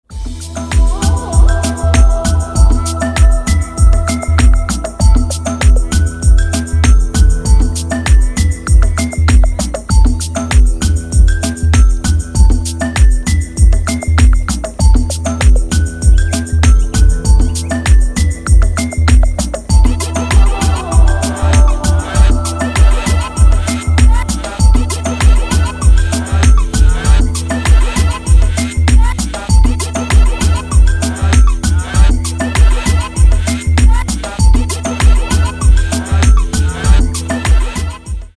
• Качество: 320, Stereo
женский голос
электронная музыка
спокойные
без слов
Electronica
chillout
Downtempo
этнические
ксилофон
Afro-House